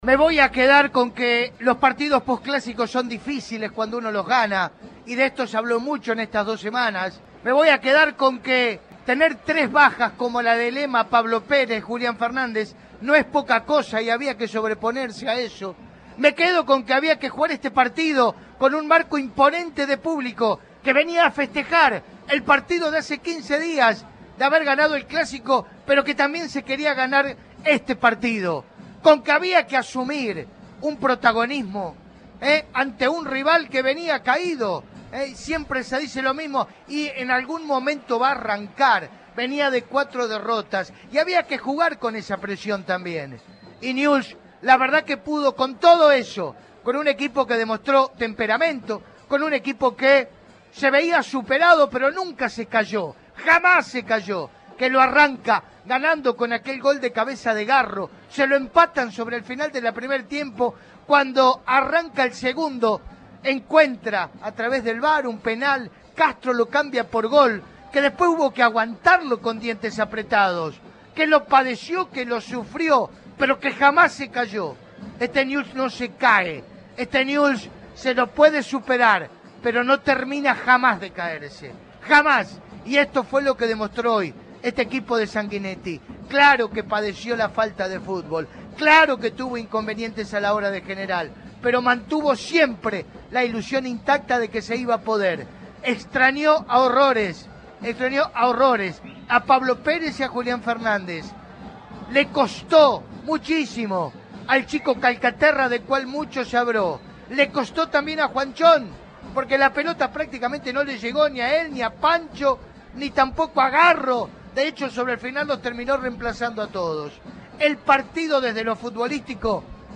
En la transmisión de "Estadio 3" por Cadena 3 Rosario